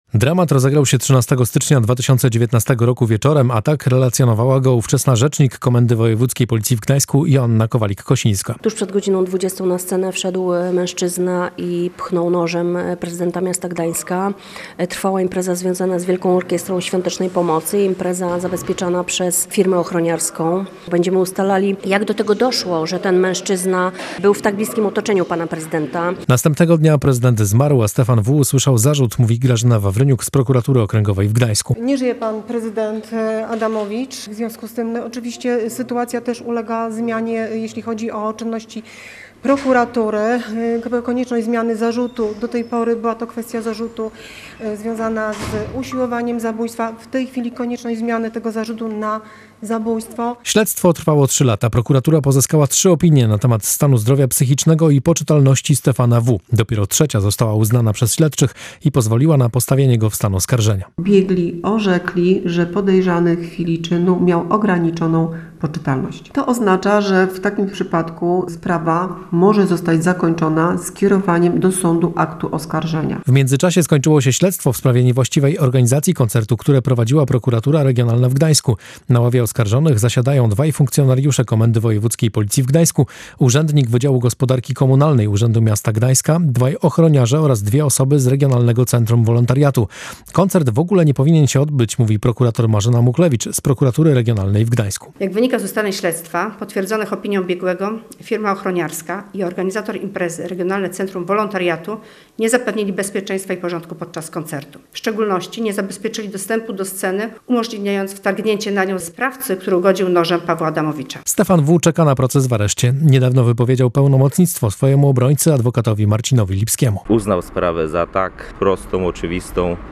POS?UCHAJ MATERIA?U NASZEGO REPORTERA: